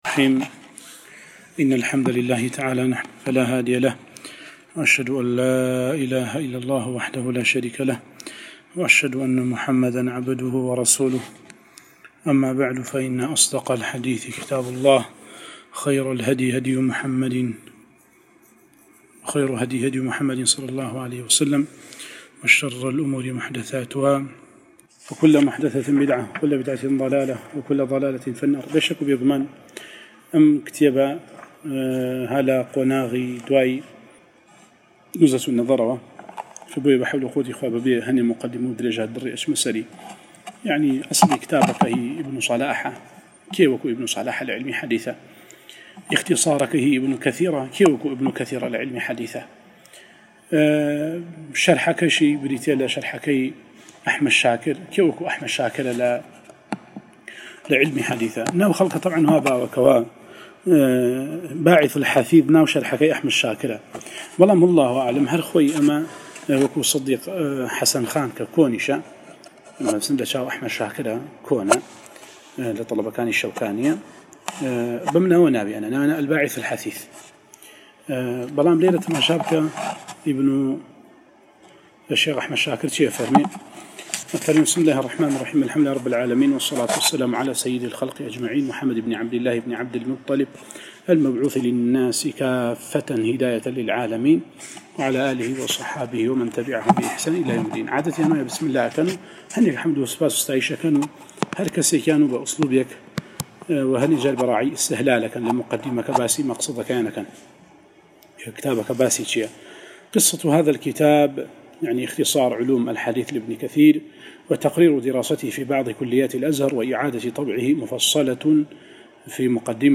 القراءة والتعليق على مواضع من الباعث الحثيث ـ1